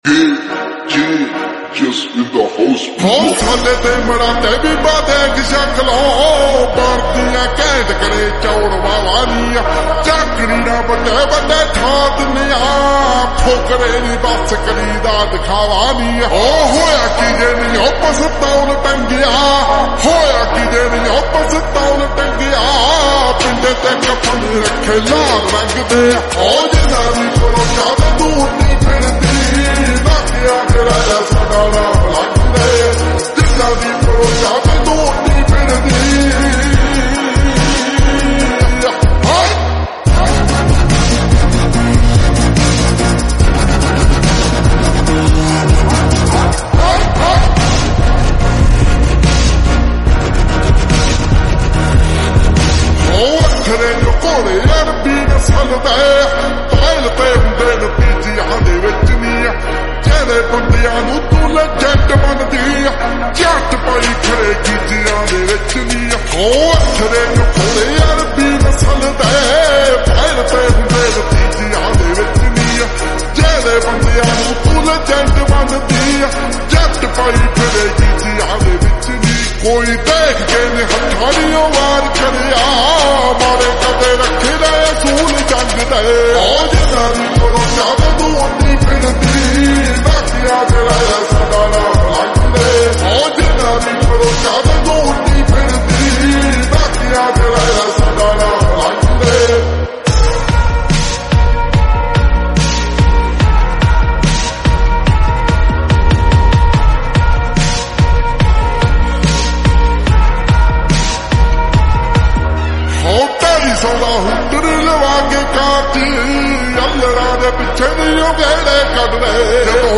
(SOLVED REVERB 🥵🎧🔥)